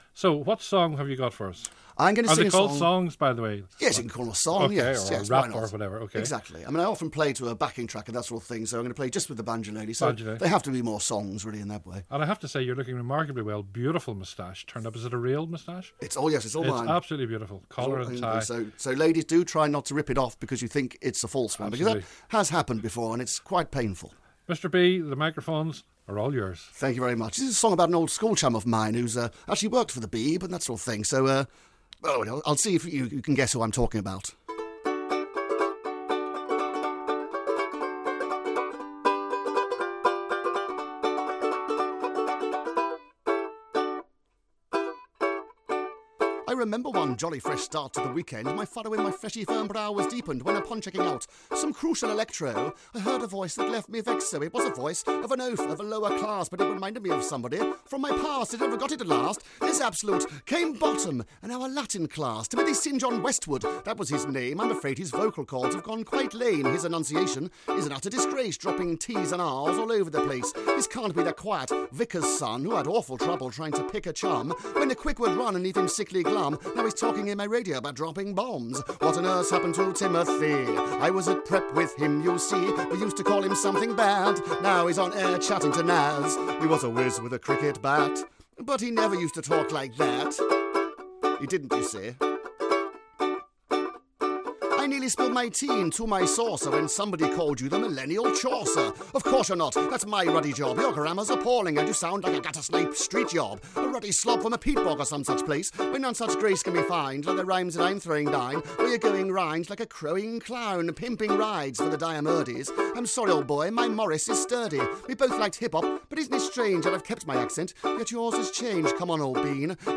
acoustic version